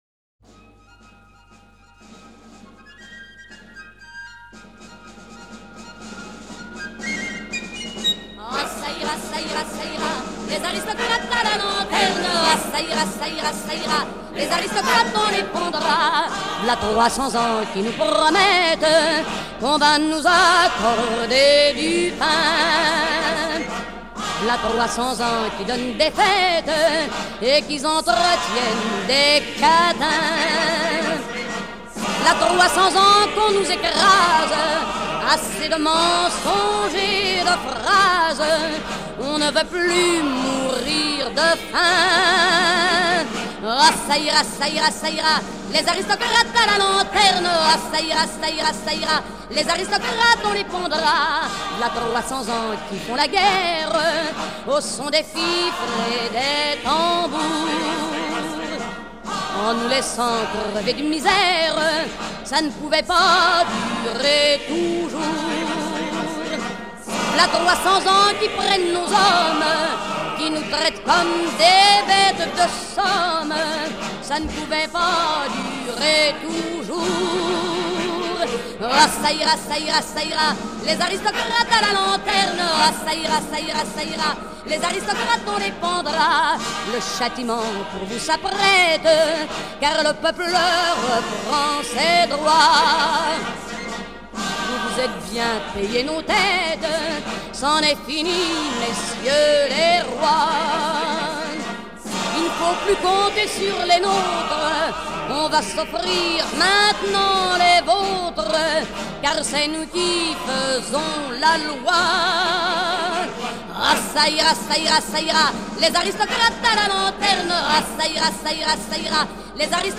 Chants révolutionnaires